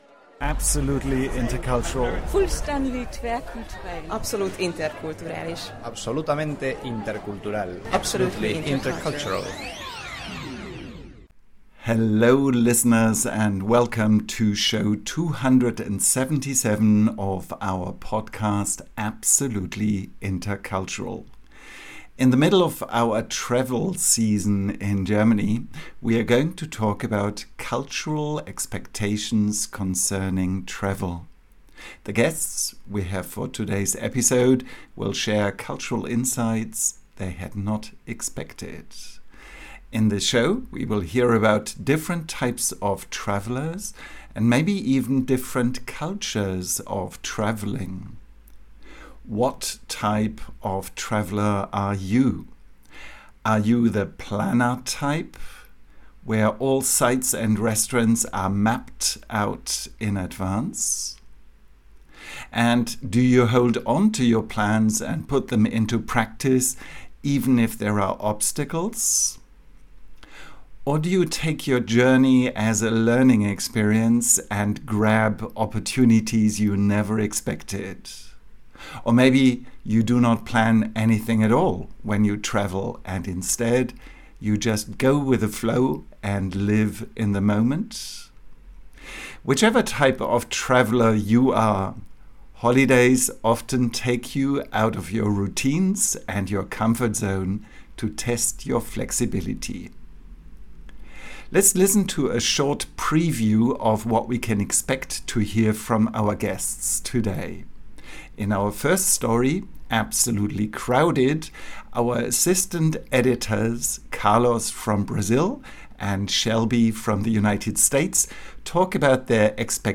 The guests we have for today’s episode will share cultural insights that they had not expected.